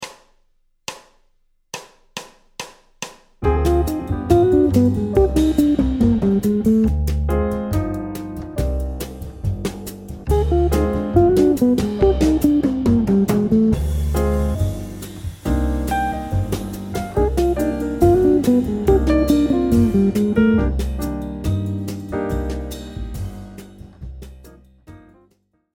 Motif Jazz : (E2) Chromatismes ascendants continus
utilise la gamme de C mineur harmonique pour finir par un chromatisme vers la note A qui est caractéristique du mineur mélodique.
Phrase-17-Cadence-ii-V7-i-min.mp3